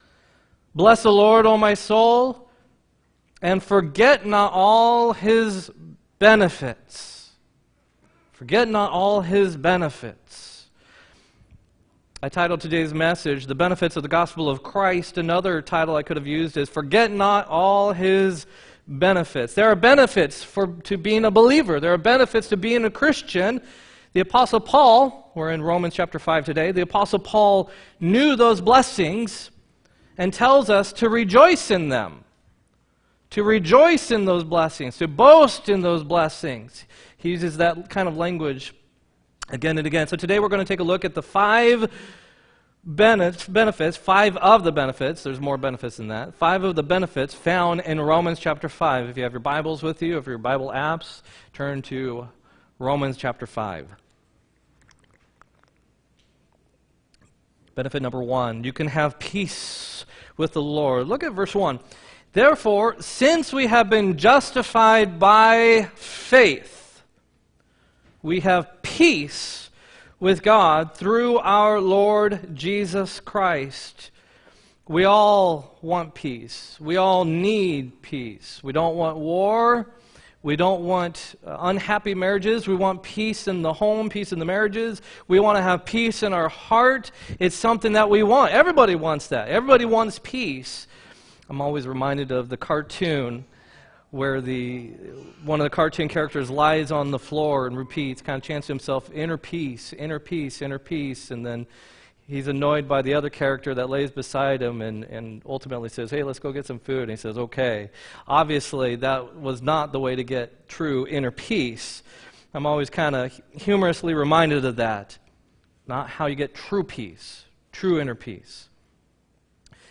7-14-18 sermon